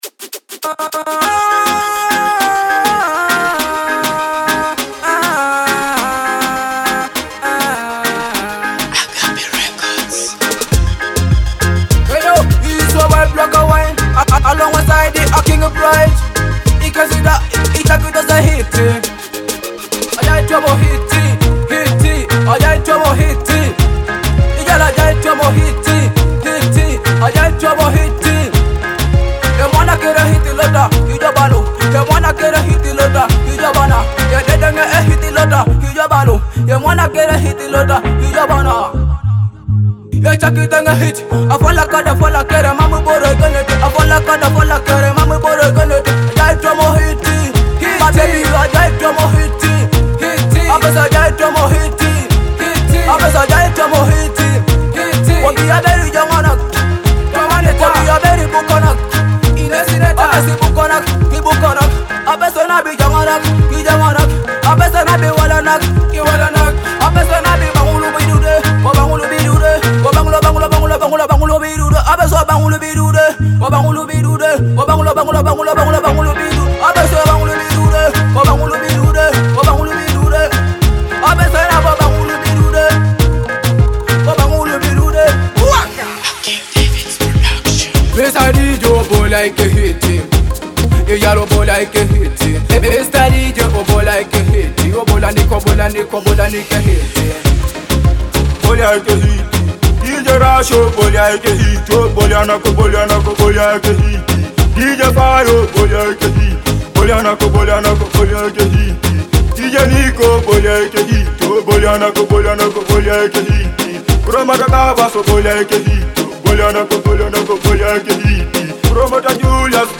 dancehall hit